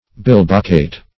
bilboquet - definition of bilboquet - synonyms, pronunciation, spelling from Free Dictionary Search Result for " bilboquet" : The Collaborative International Dictionary of English v.0.48: Bilboquet \Bil"bo*quet\, n. [F.] The toy called cup and ball .